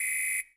6WHISTLE L23.wav